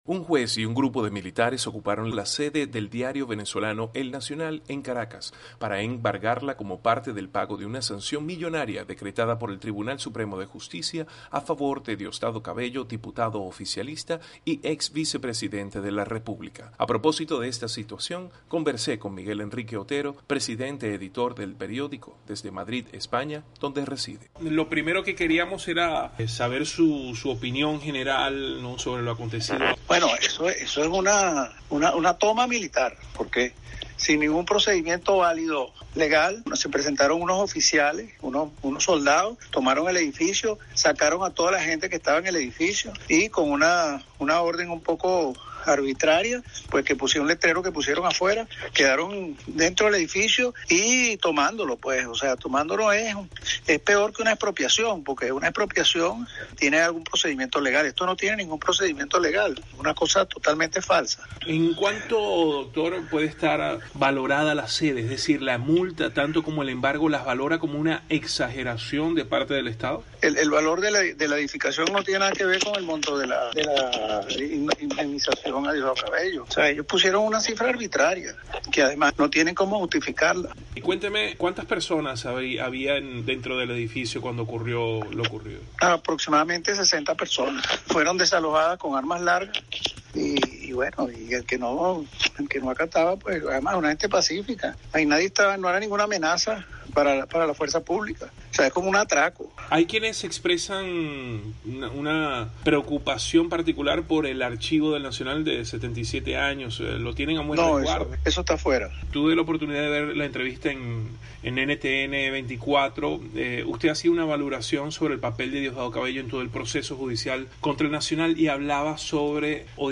Entrevista a Miguel Henrique Otero